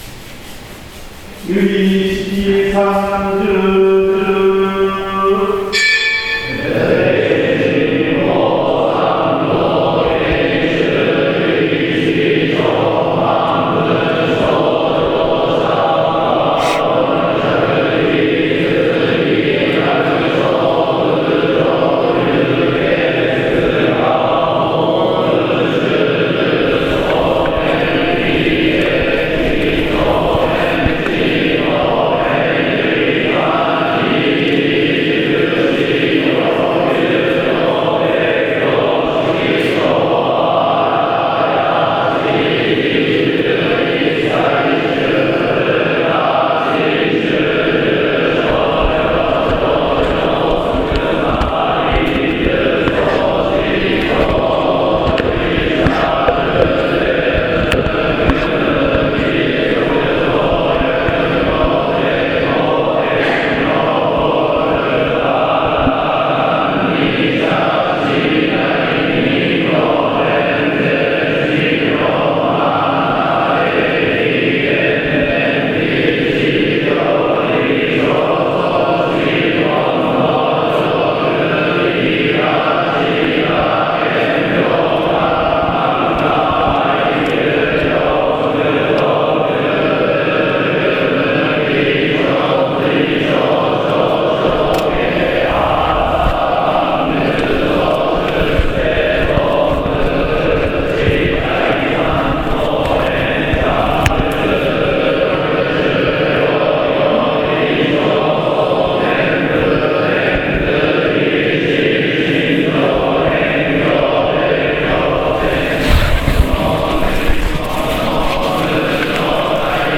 ・法相宗のお経の特徴は、重厚で荘厳な感じがするお経です。